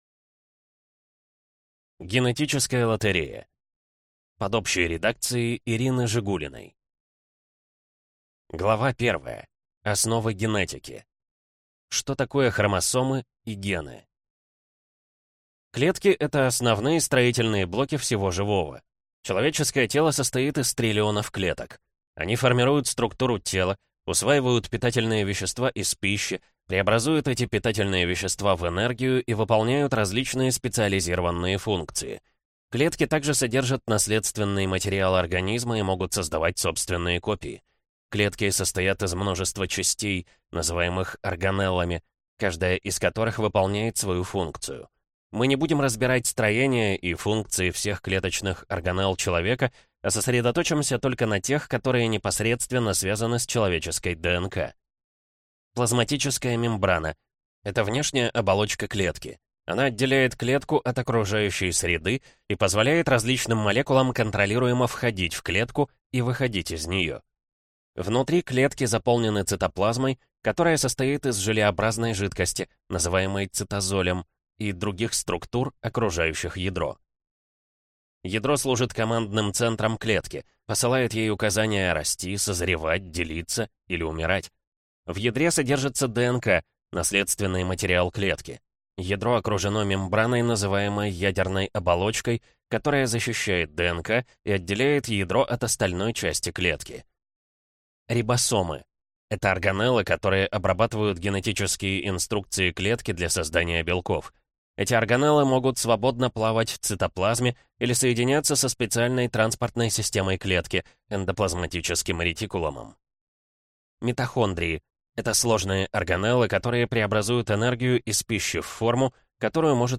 Аудиокнига Генетическая лотерея | Библиотека аудиокниг
Прослушать и бесплатно скачать фрагмент аудиокниги